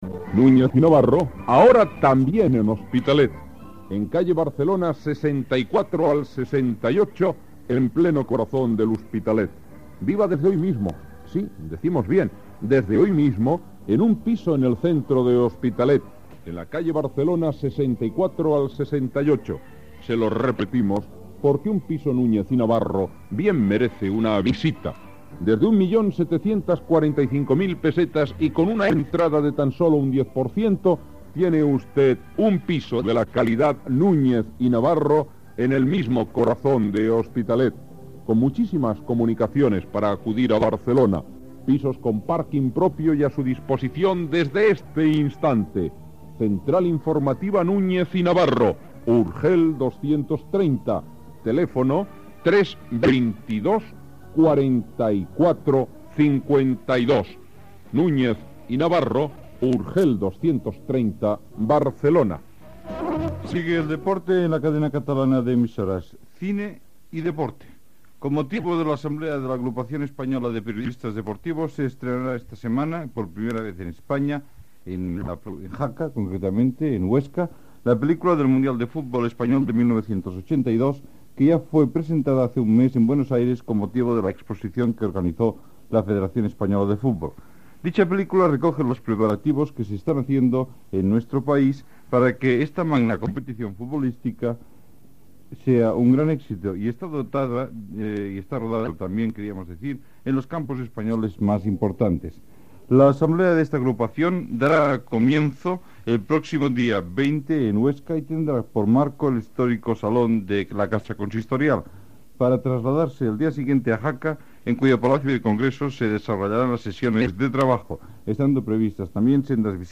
Publicitat, estrena de la pel·licula sobre els preparatius del Mundial de Futbol 1982, boxa (combat de Mohamed Alí), publicitat
Esportiu